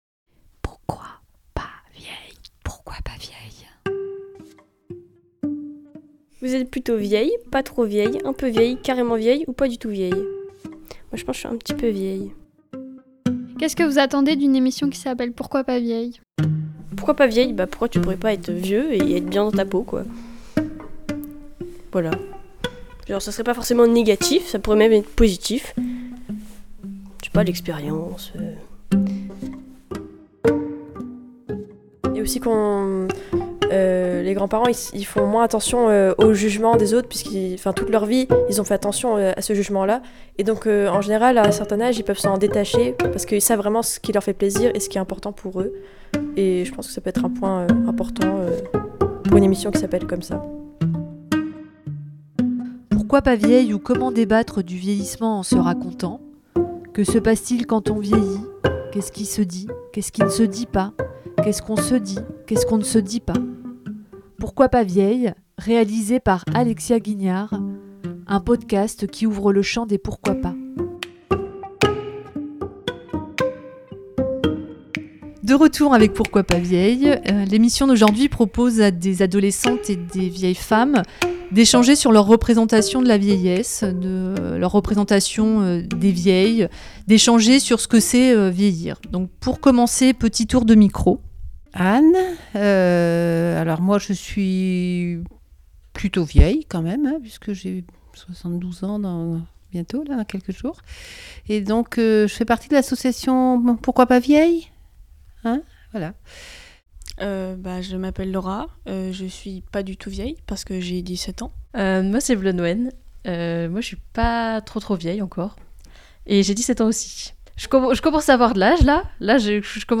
Cinq adolescentes de 17 ans et trois femmes de 69 ans à 76 ans se sont rencontrées à Quimper pour discuter, échanger sur leurs représentations et leurs expériences de la vieillesse.